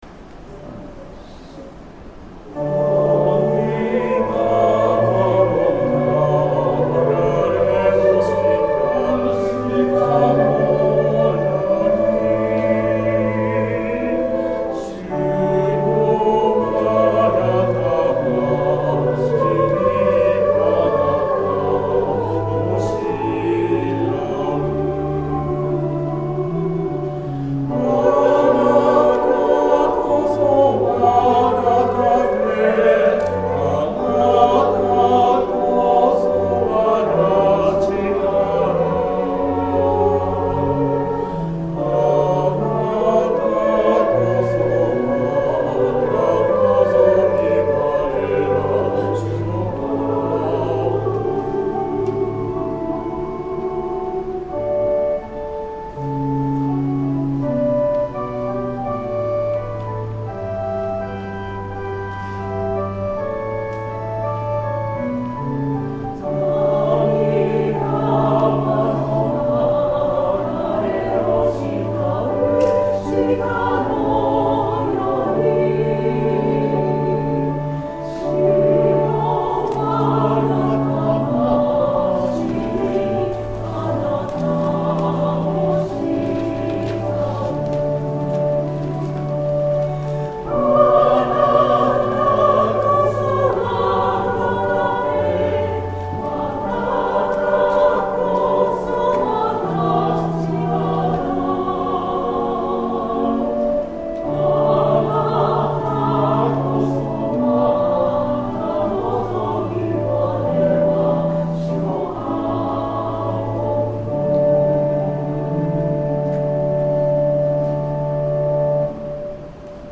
Tonality = D
練習: 2024/8/6 修養会 分科会：聖歌隊 2-3回目のみ